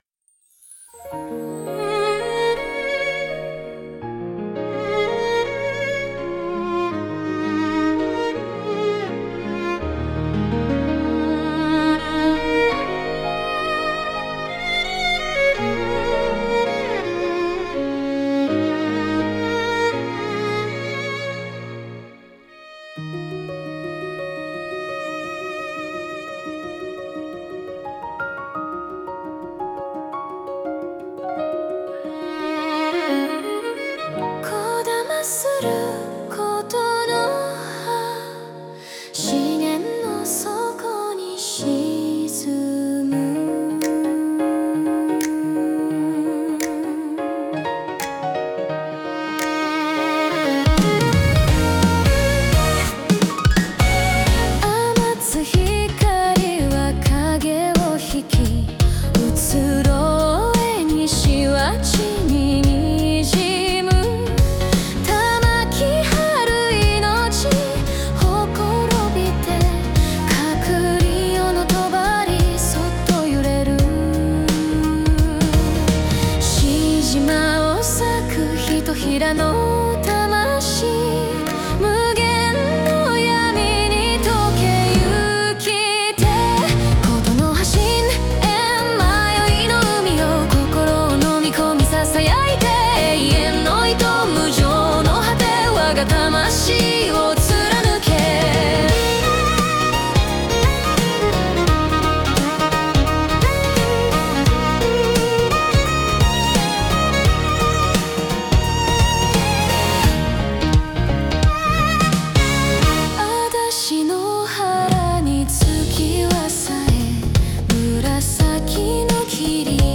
ネオジャパン , 和風
イメージ：ネオ和,女性ボーカル,かっこいい,日本